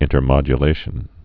(ĭntər-mŏjə-lāshən)